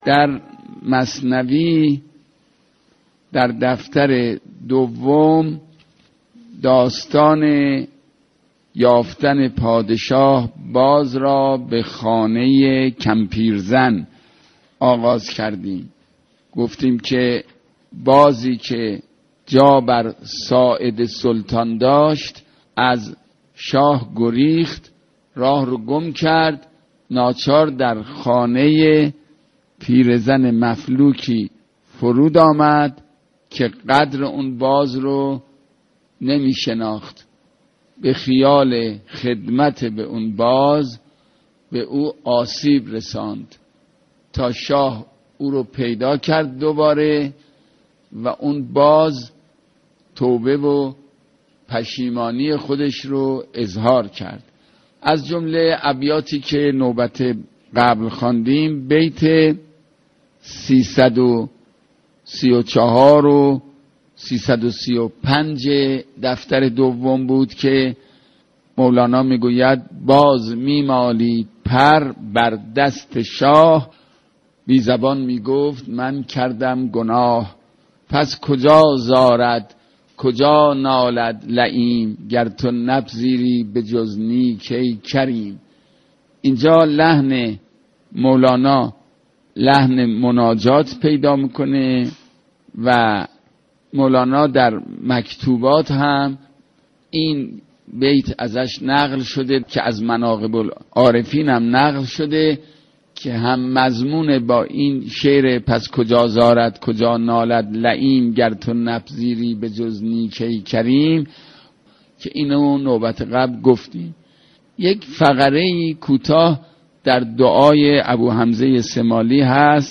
در سلسله بحث های درس مثنوی كه از رادیو فرهنگ پخش می شود دكتر حداد عادل با قرائت اشعار مولانا به شرح و تفسیر اشعار این شاعر بلند آوازه می پردازد .